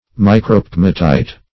Search Result for " micropegmatite" : The Collaborative International Dictionary of English v.0.48: Micropegmatite \Mi`cro*peg"ma*tite\, n. [Micro- + pegmatite.]